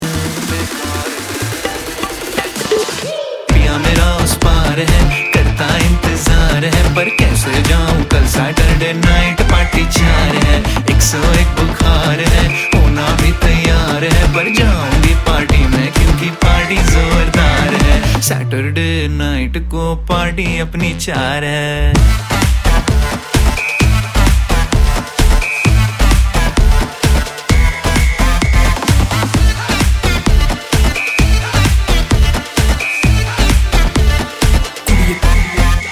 Party song of the year!
Click here to listen to foot tapping audio clip!